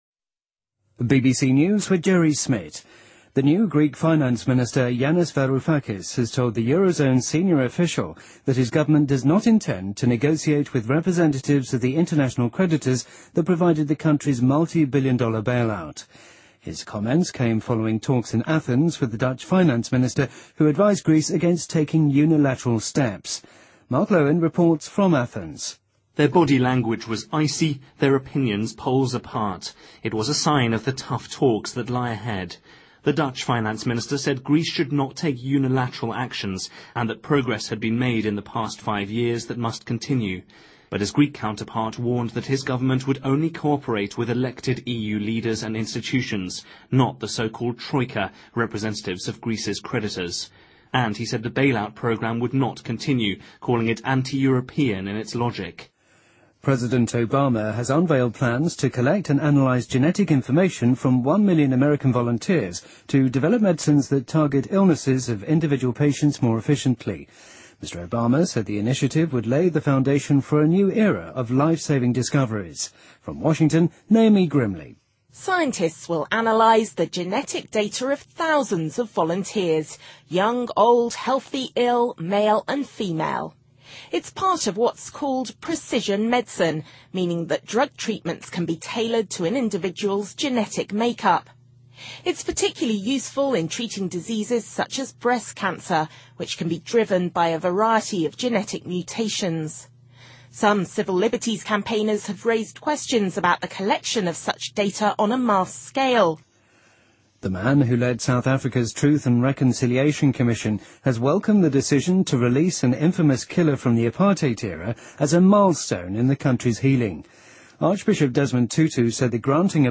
BBC news:2015-01-31����|BBC��������